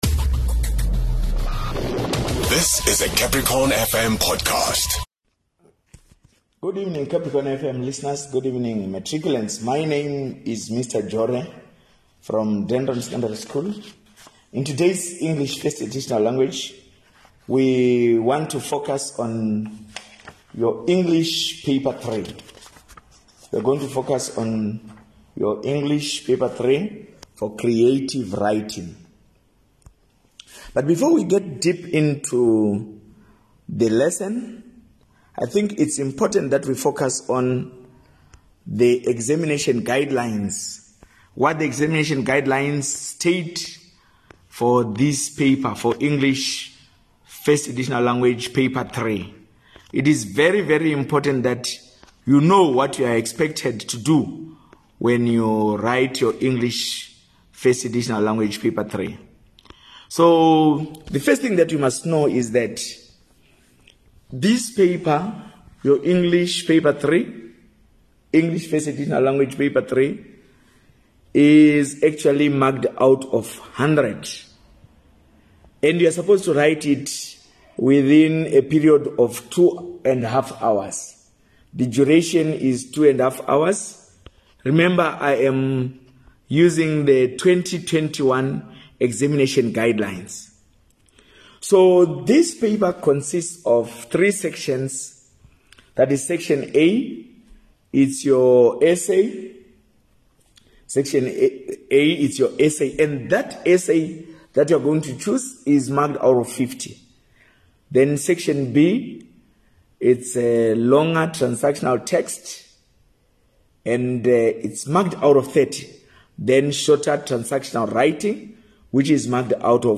As the year edges to an end, the Limpopo Department of Basic Education has dedicated time everyday on CapricornFM to helping Grade12 learners catch up on various lessons